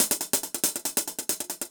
Index of /musicradar/ultimate-hihat-samples/140bpm
UHH_AcoustiHatB_140-01.wav